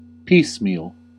Ääntäminen
US RP : IPA : /ˈpiːs.miːl/